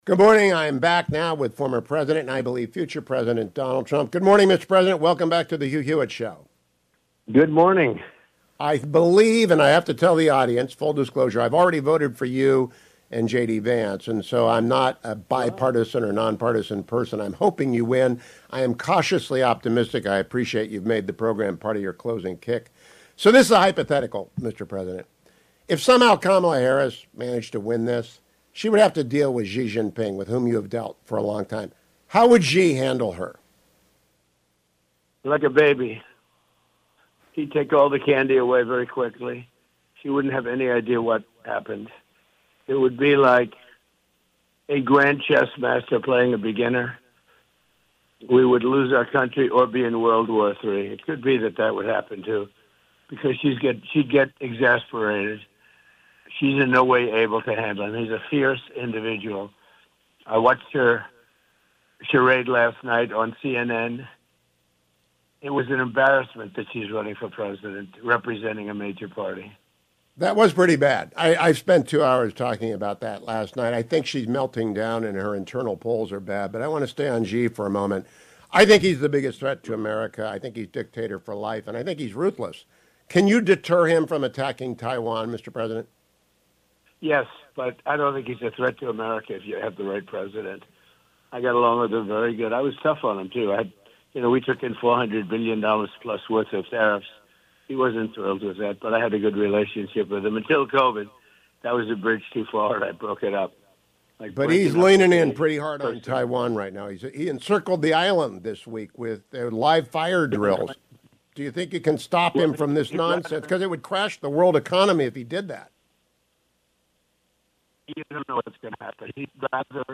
Former President Trump joined me this morning: